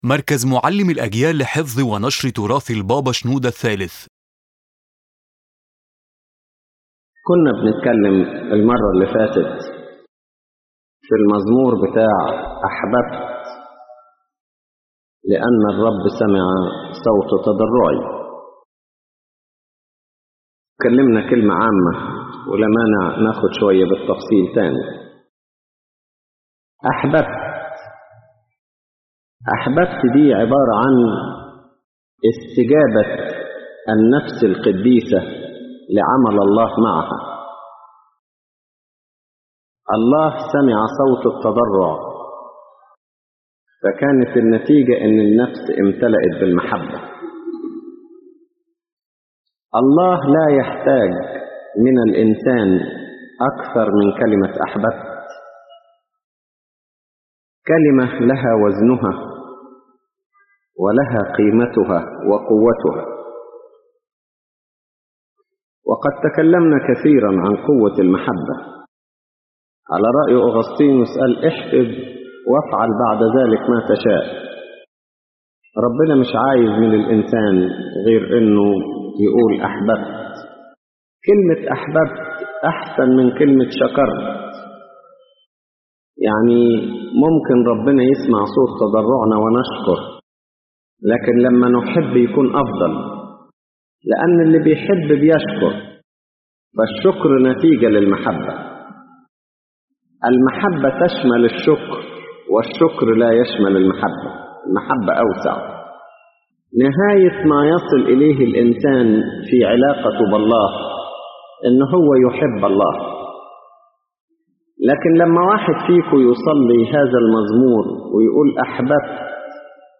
⬇ تحميل المحاضرة أولًا: «أحببت» كاستجابة لعمل الله كلمة «أحببت» هي استجابة النفس لعمل الله معها.